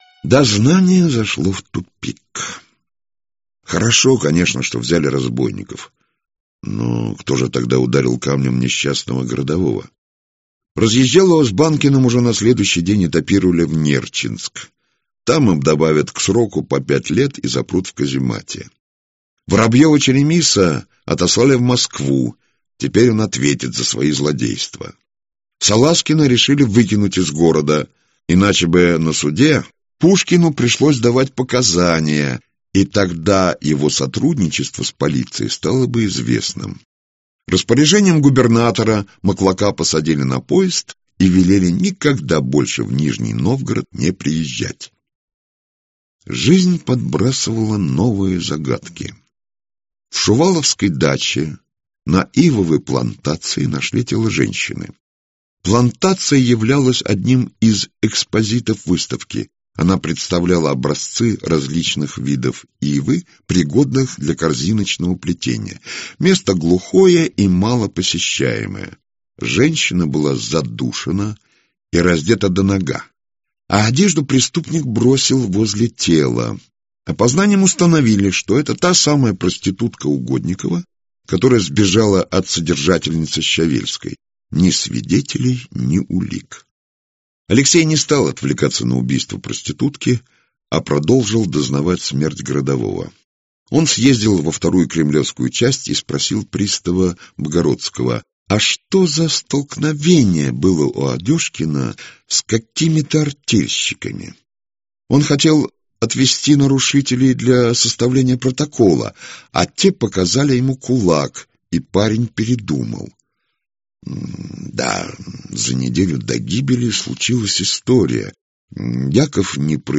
Аудиокнига Ночные всадники (сборник) - купить, скачать и слушать онлайн | КнигоПоиск